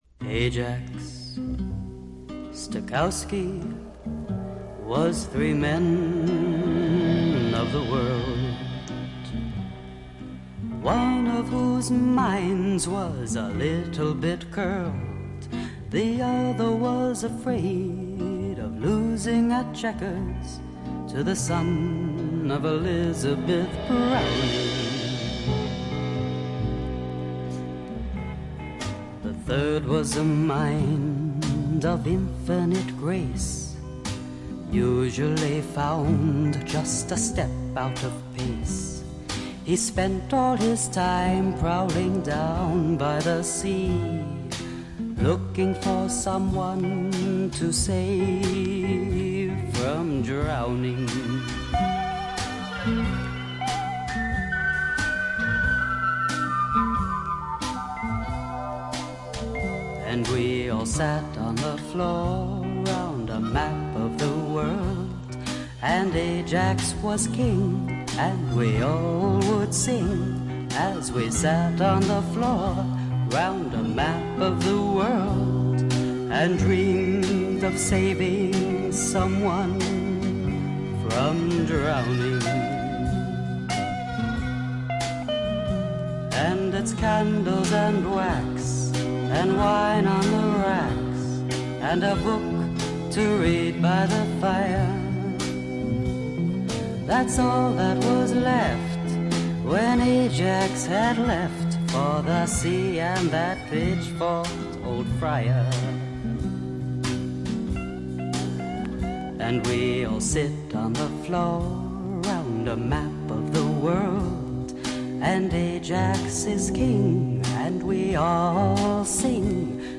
60年代気分満開の中に漂うダークでブルージーなアシッドな香りがまた良いです。
試聴曲は現品からの取り込み音源です。